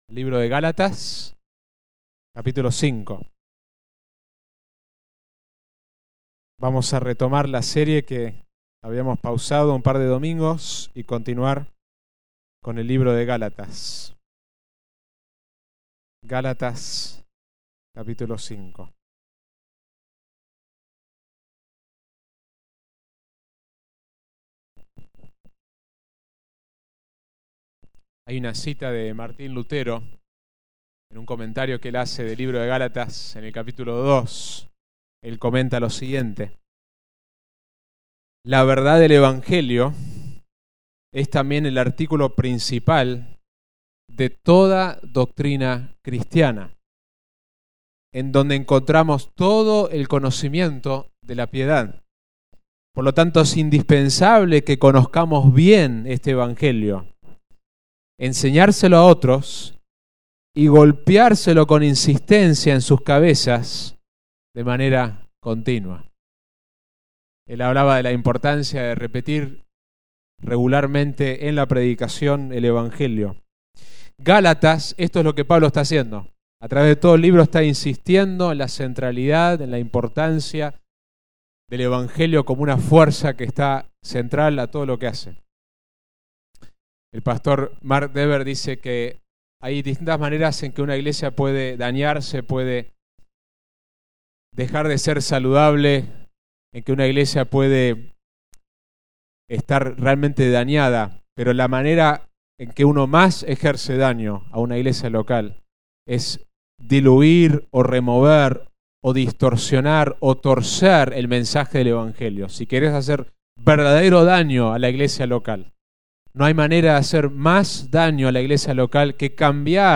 Sermón